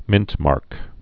(mĭntmärk)